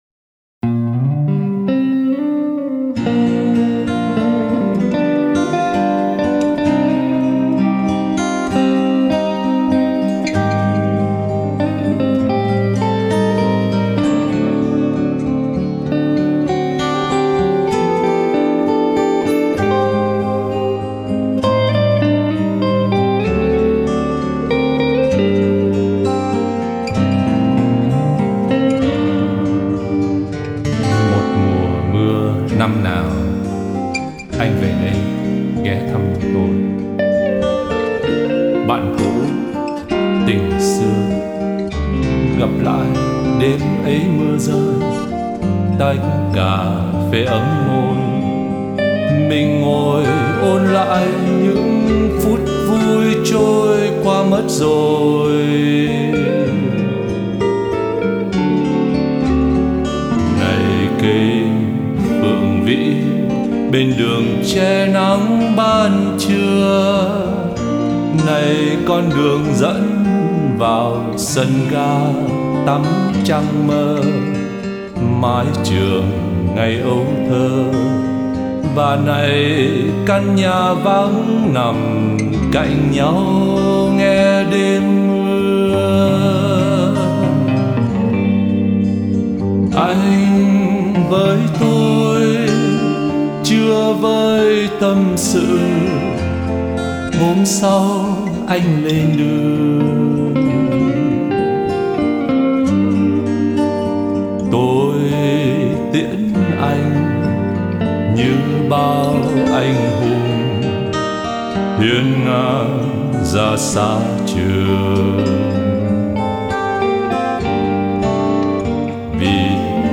Phần đầu có vẻ như nửa nói nửa hát